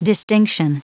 Prononciation du mot distinction en anglais (fichier audio)
Prononciation du mot : distinction